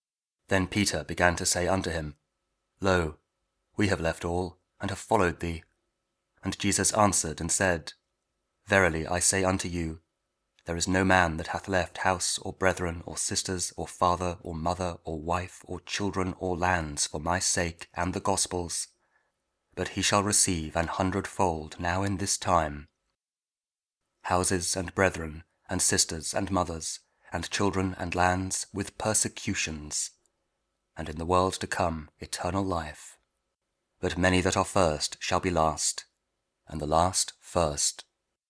Mark 10: 28-31 – Week 8 Ordinary Time, Tuesday | King James Audio Bible KJV, Spoken Word